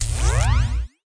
Block Zap Blast Sound Effect
Download a high-quality block zap blast sound effect.
block-zap-blast.mp3